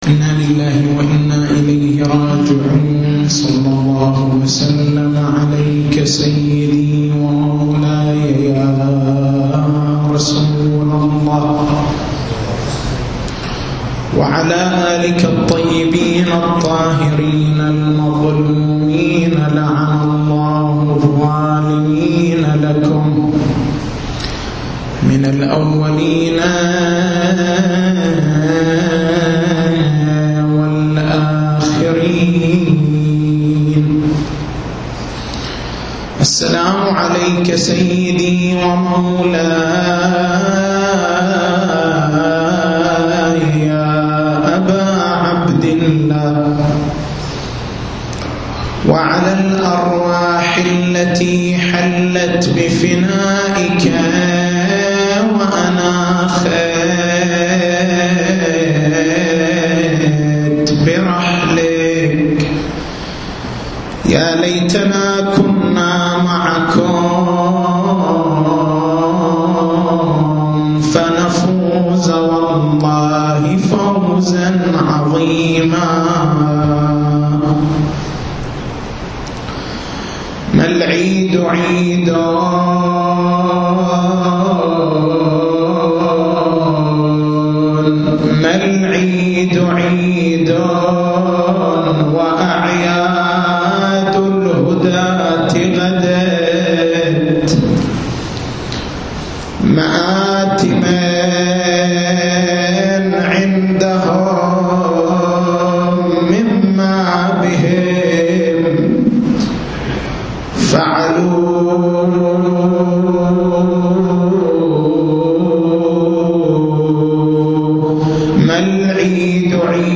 تاريخ المحاضرة: 30/09/1430 محور البحث: ما معنى وداع شهر رمضان من خلال الأدعية الواردة عن أهل البيت (عليهم السلام) رغم كونه زمانًا لا إدراك له؟